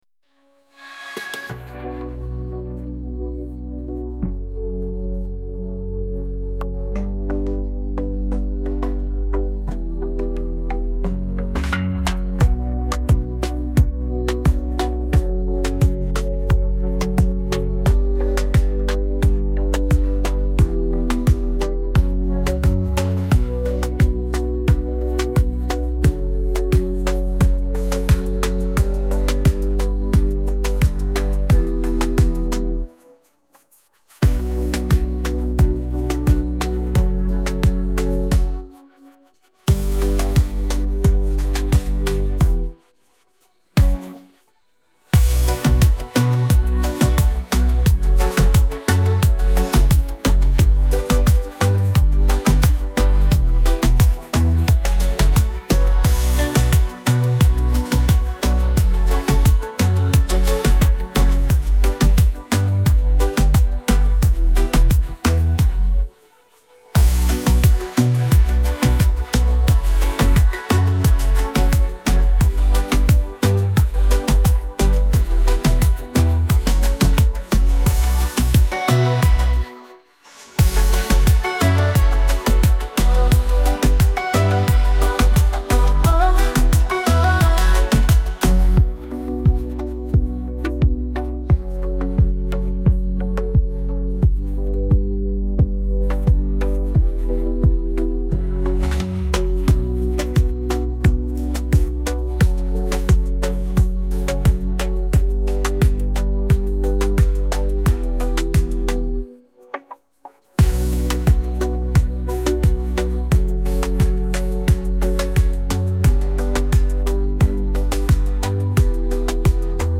موسیقی بی کلام دیپ هاوس موسیقی بی کلام ریتمیک آرام